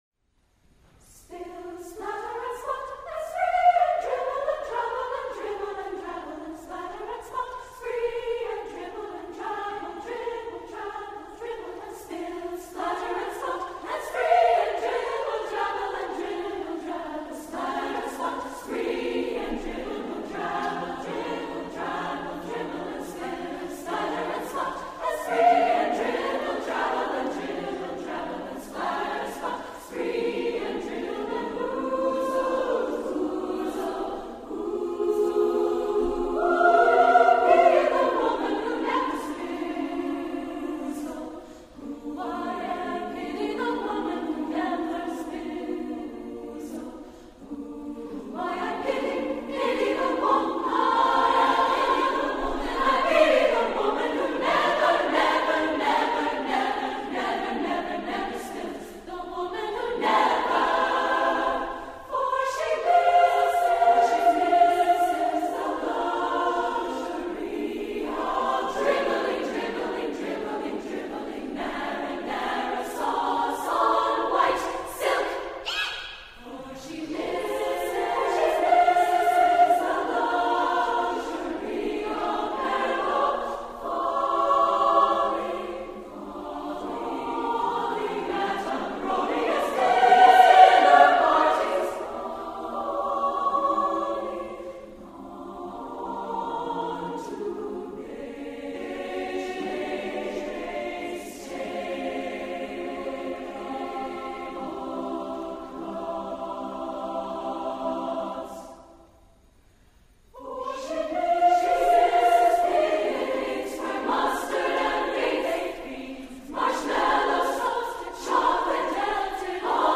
A gutsy, sensual song in praise of messy women.
SSAA a cappella
A gutsy, sensual blues setting